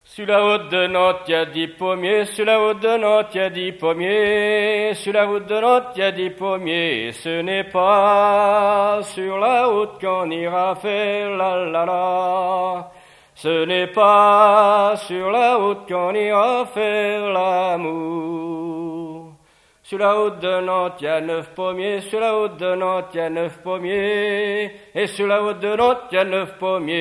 Mémoires et Patrimoines vivants - RaddO est une base de données d'archives iconographiques et sonores.
gestuel : à marcher
Genre énumérative
Pièce musicale inédite